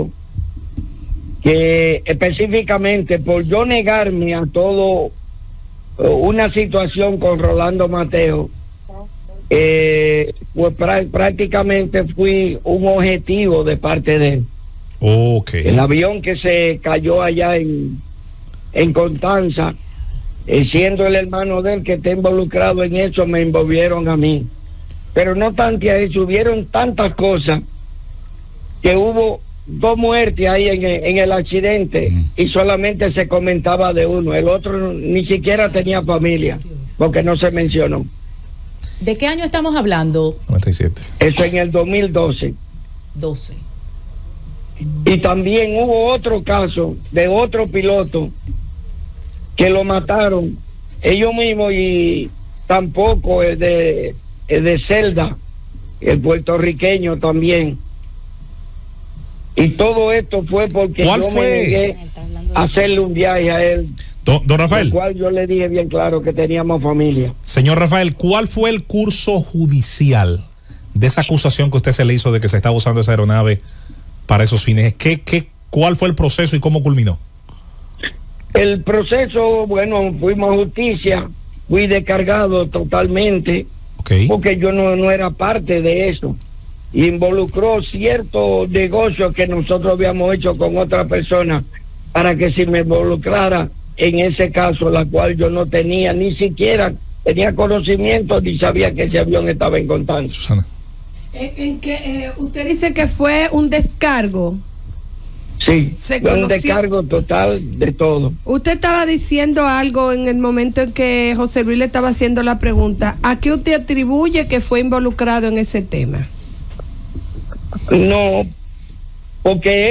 durante una entrevista telefónica en el programa radial nacional “El Gobierno de la Mañana”, transmitido por la emisora Z101.